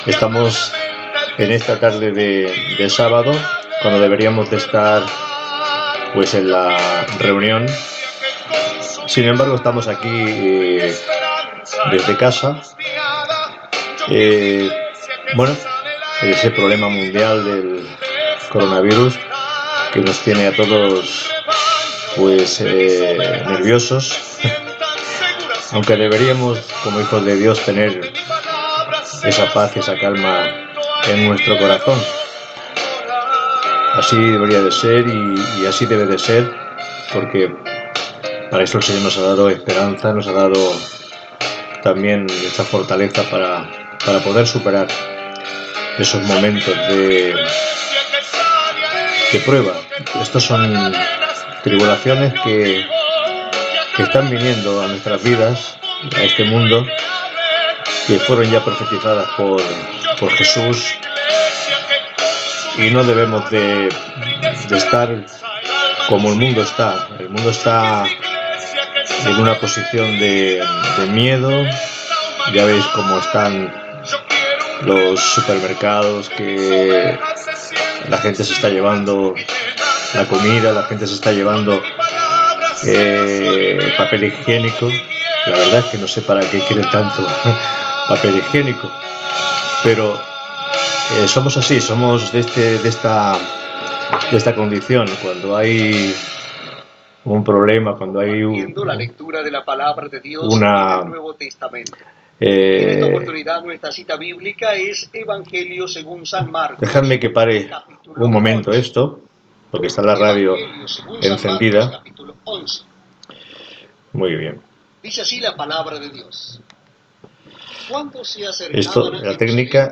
Espai realitzat des de casa durant el confinament de la padèmia de la Covid 19, comentari sobre la situació del moment, esment al canal radiofònic a Tune in
Religió